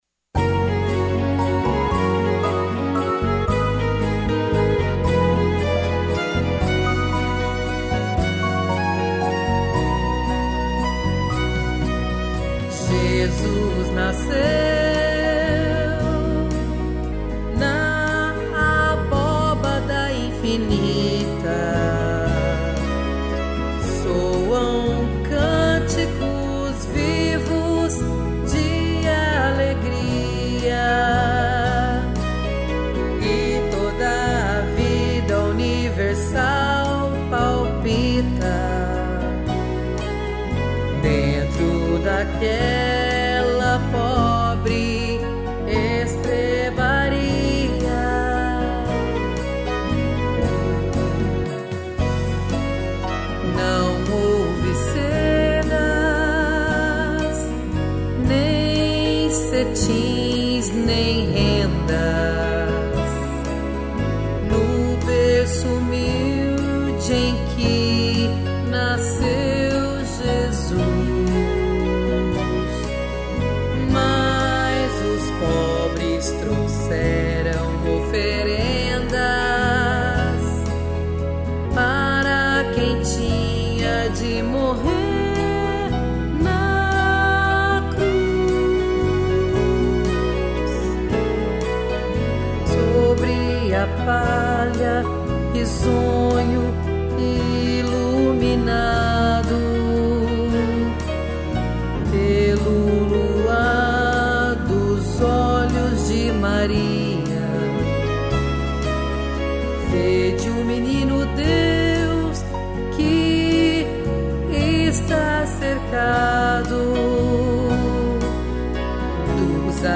piano, violino e cello